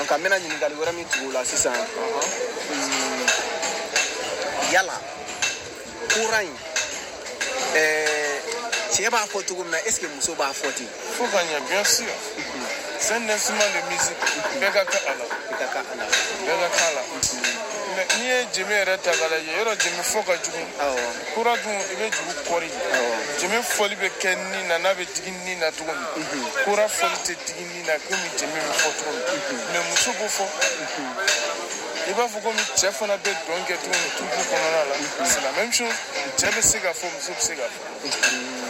Dialogue translation: